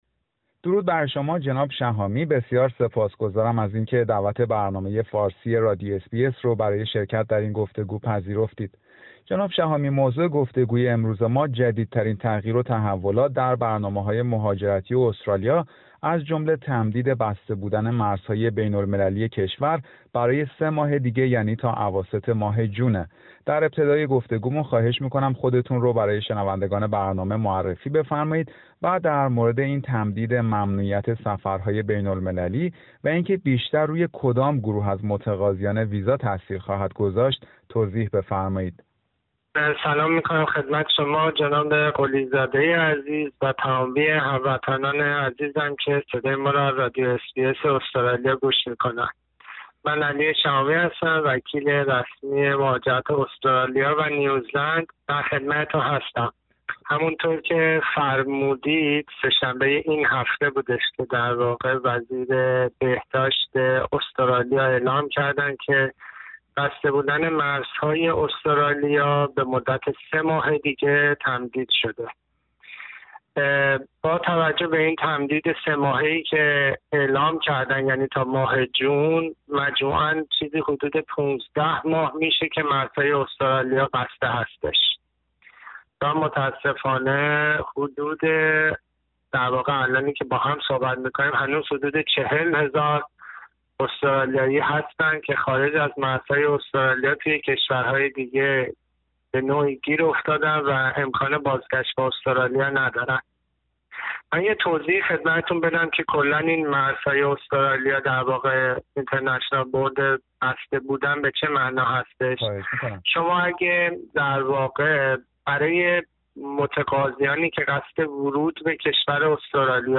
گفتگویی در مورد تمدید بسته بودن مرزهای بین المللی استرالیا و تاثیراتی که روی متقاضیان ویزا خواهد داشت